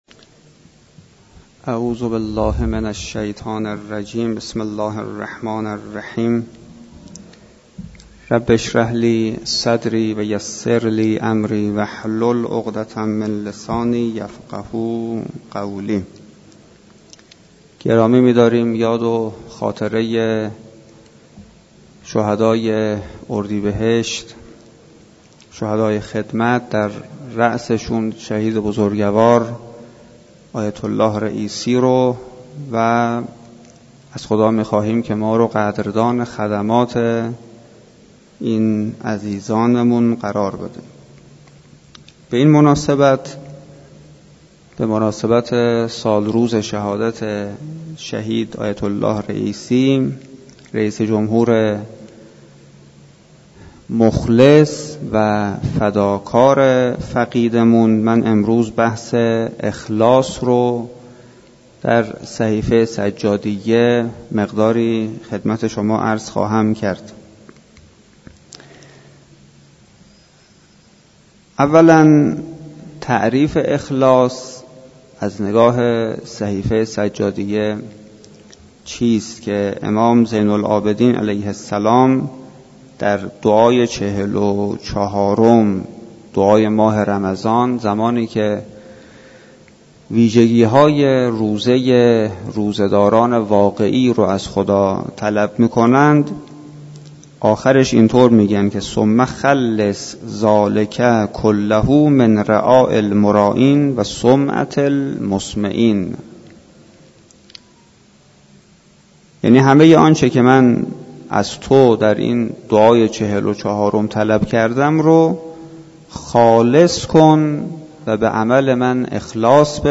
سخنرانی
در مسجد دانشگاه کاشان